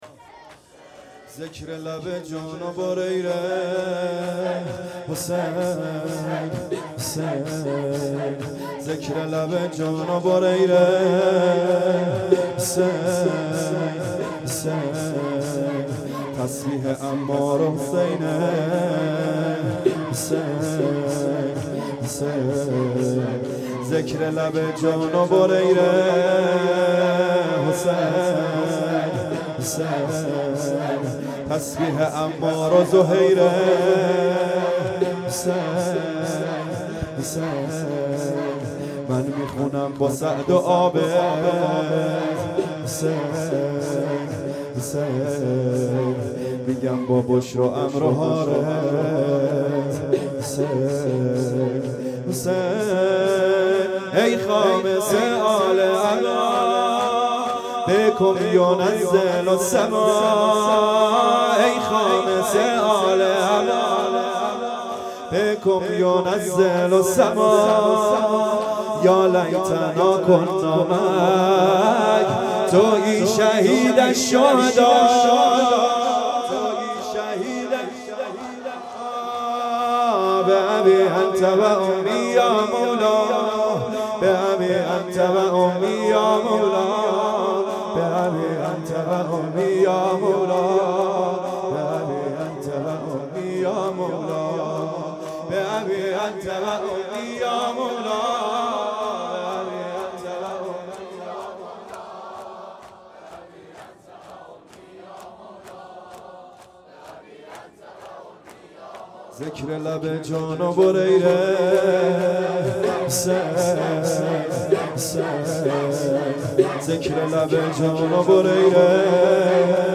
محرم 1440_ شب دوم